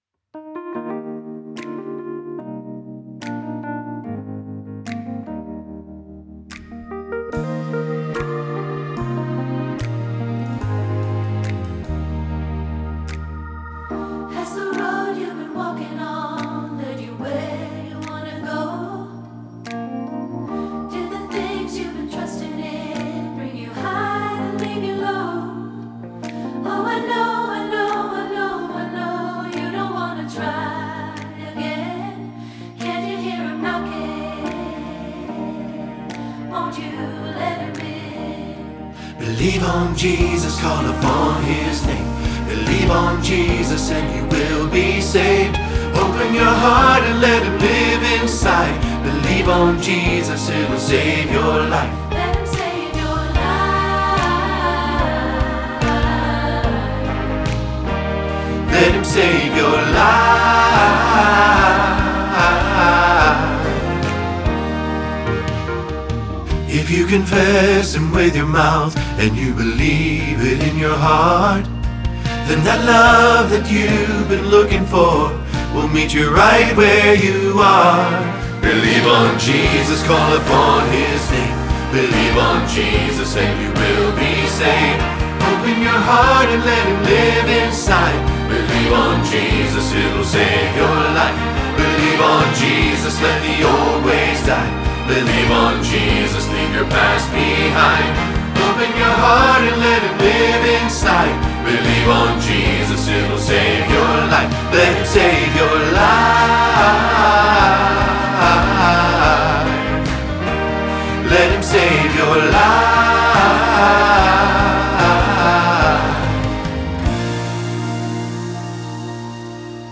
Believe on Jesus – Bass – Hilltop Choir
Believe-on-Jesus-Bass.mp3